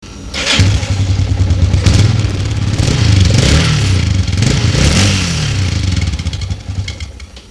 Dzwiek XT`ka (0,3 MB mp3) Powrot